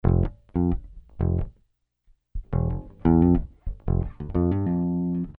Hiphop music bass loop - 90bpm 73